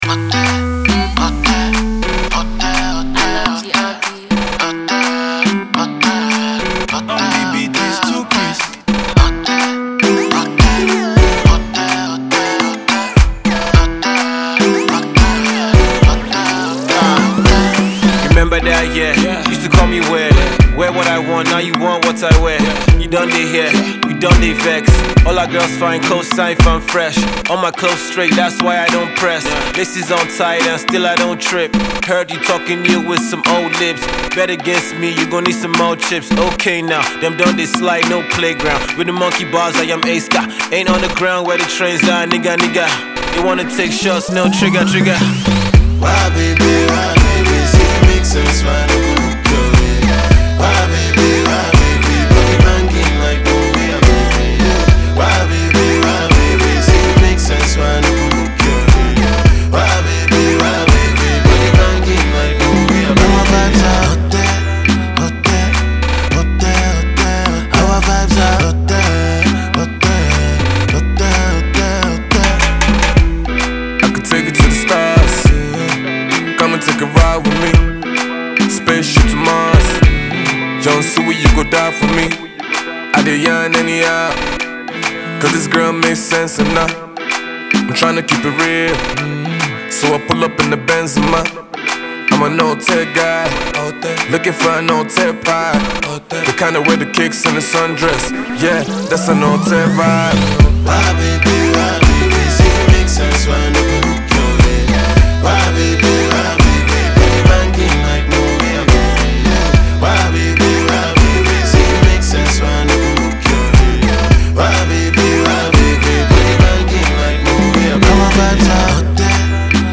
a Vibrant uptempo jam